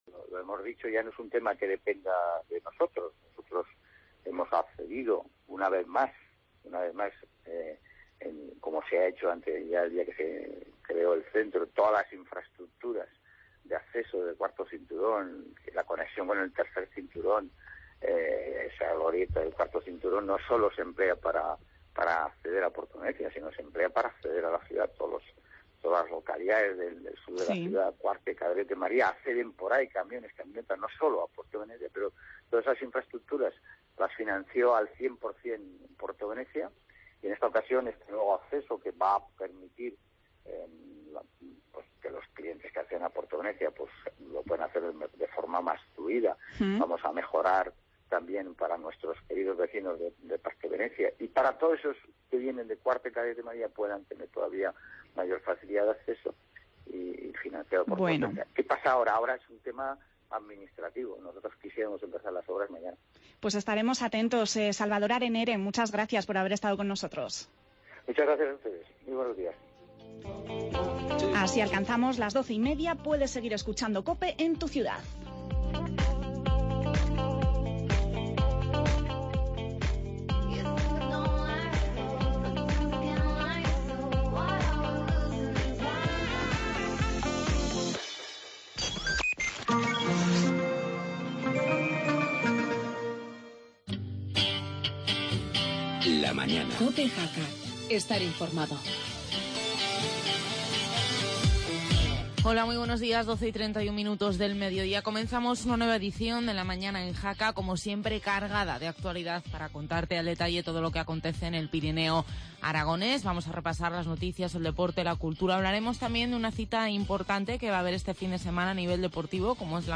tertulia agraria